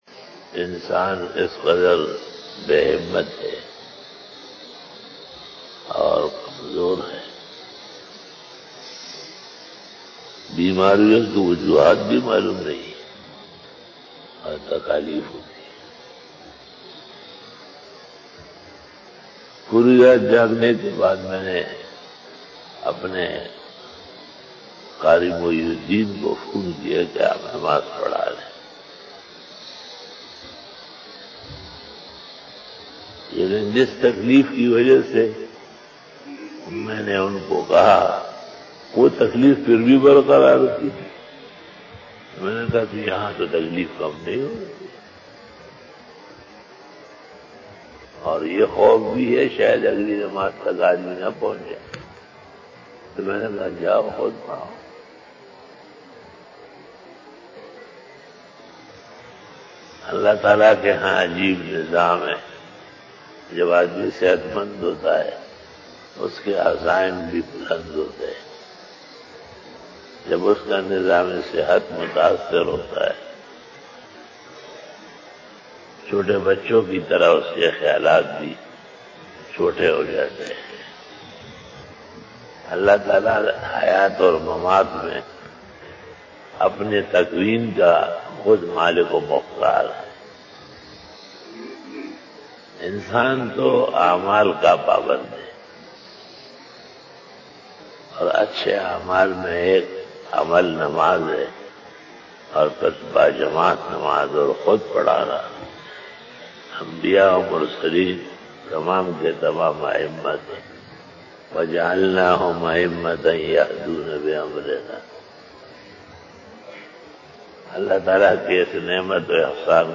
Fajar bayan 14 September 2020 (25 Muharram 1442HJ) Monday
بعد نماز فجر بیان 14 ستمبر 2020ء بمطابق 25 محرم الحرام 1442ھ بروزپیر